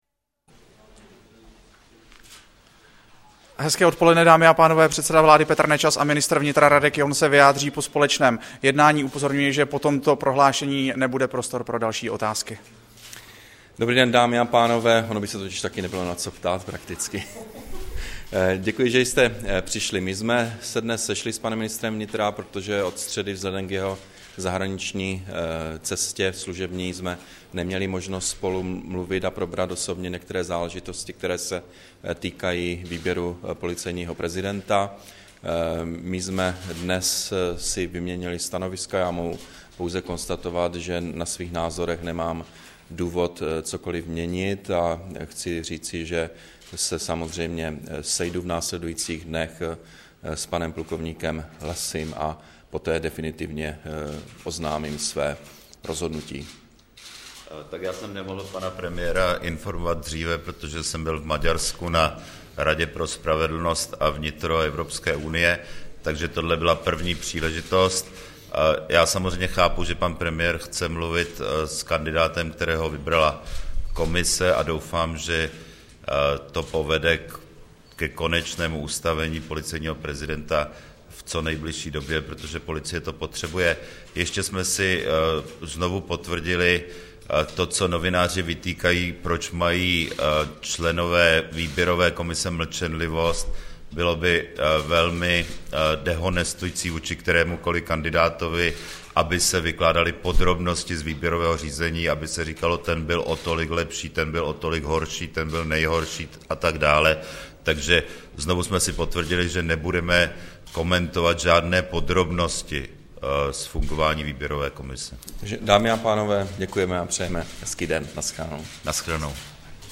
Brífink premiéra a ministra vnitra ke jmenování nového policejního prezidenta, 21. ledna 2011